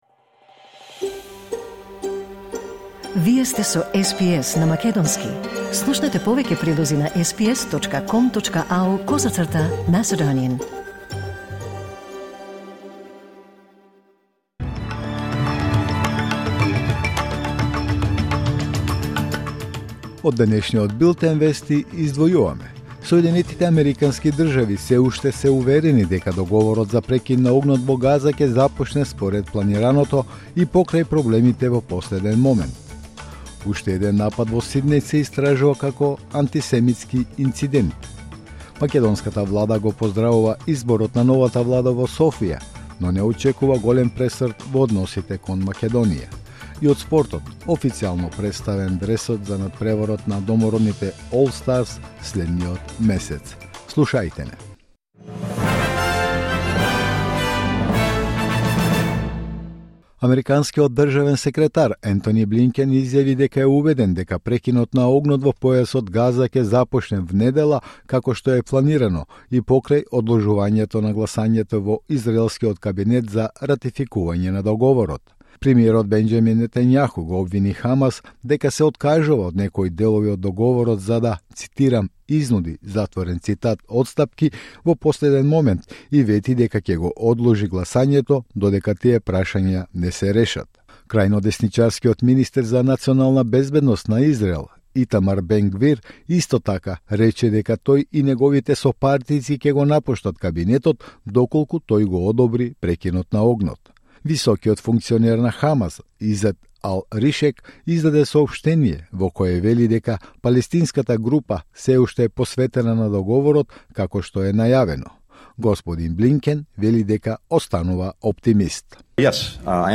Вести на СБС на македонски 17 Јануари 2025